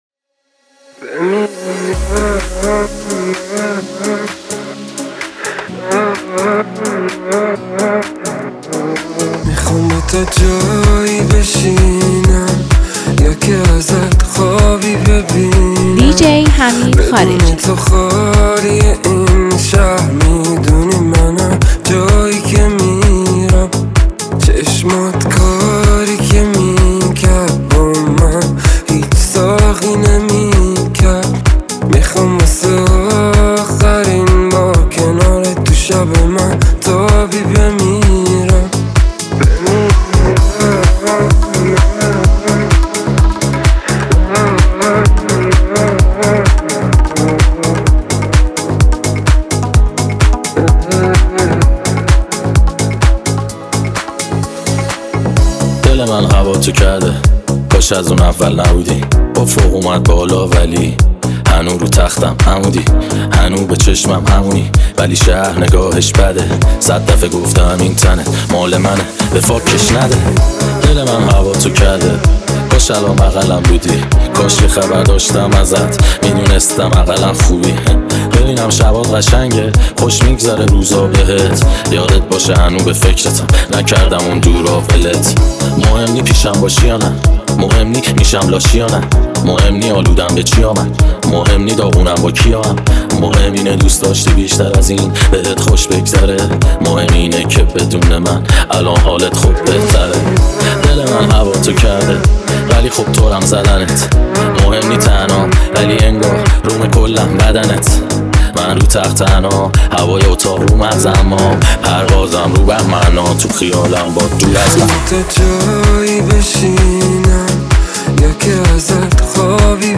انفجار رپ ترکیبی
این میکس رپ ترکیبی رو از دست ندید!